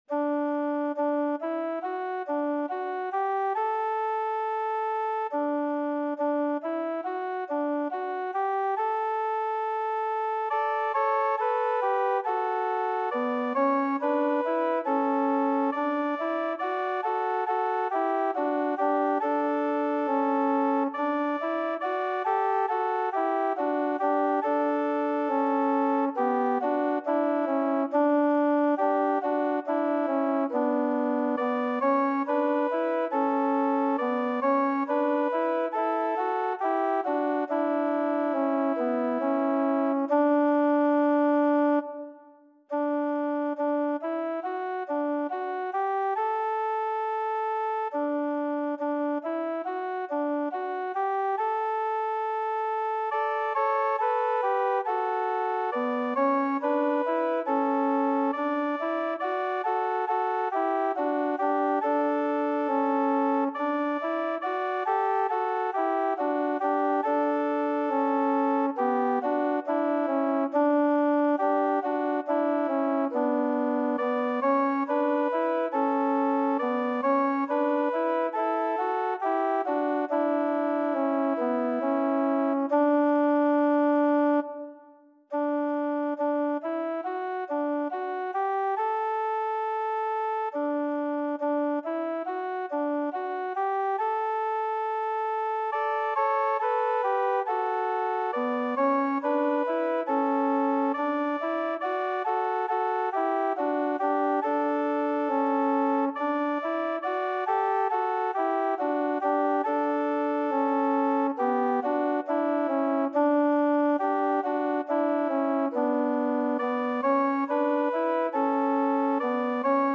Number of voices: 4vv Voicing: SATB Genre: Sacred, Hymn, Anthem Meter: 88. 44. 88 with alleluias